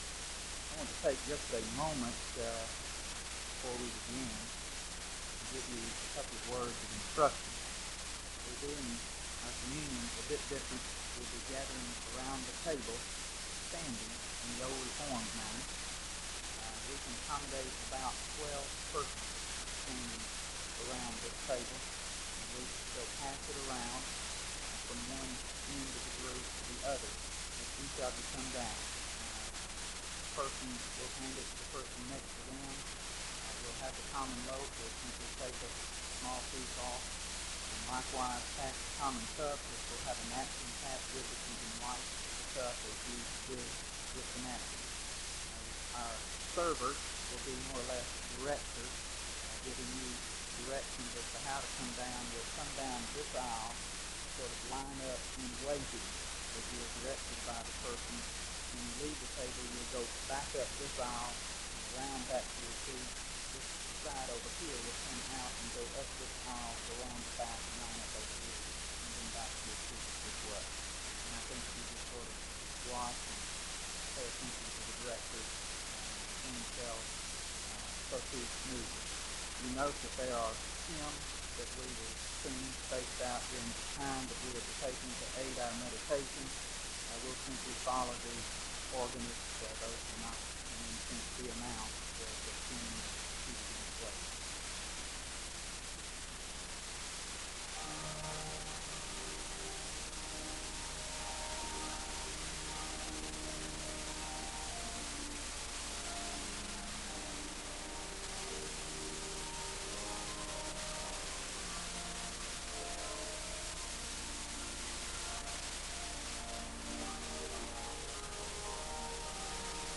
Audio quality is very poor.
The choir sings a song of worship (01:36-03:49). A speaker leads in a responsive reading from 1 Corinthians 11:23-32, and he leads in a word of prayer (04:50-06:29).